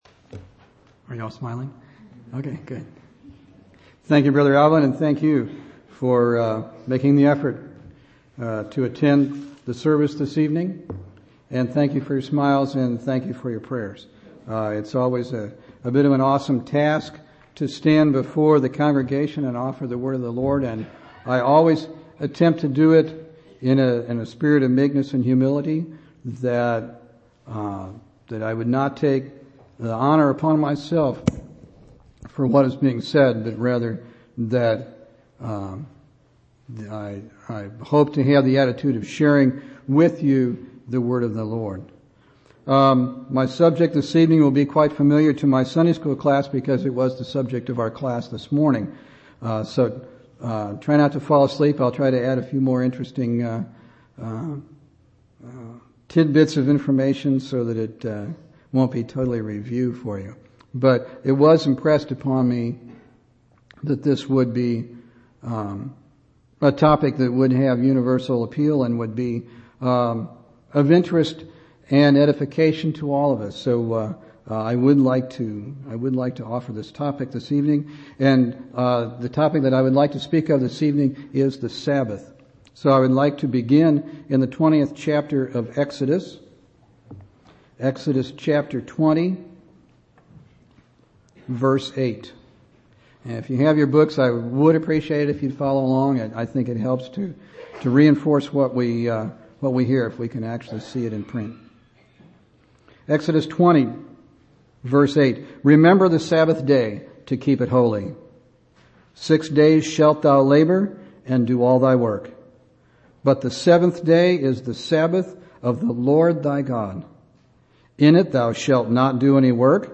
11/2/2003 Location: Temple Lot Local Event